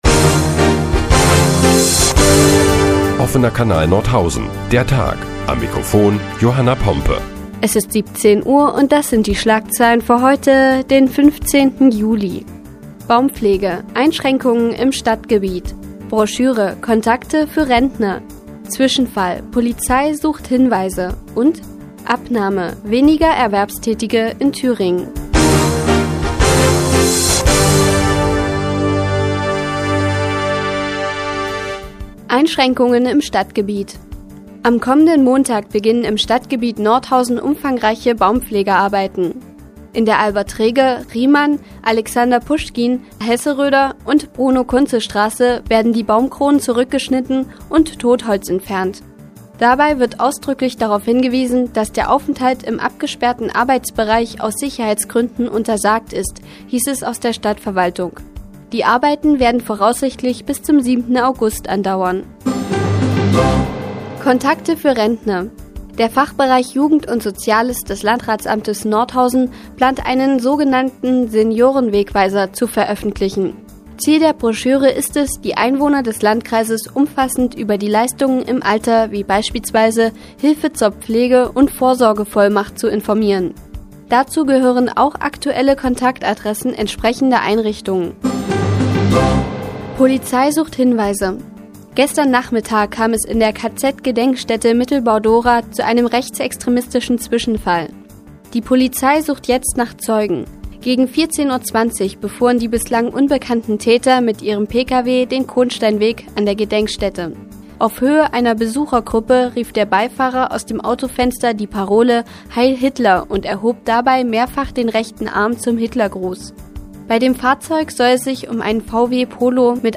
Die tägliche Nachrichtensendung des OKN ist nun auch in der nnz zu hören. Heute geht es unter anderem geht es unter anderem um weniger Erwerbstätige in Thüringen und rechtsextremistische Parolen an der KZ-Gedenkstätte.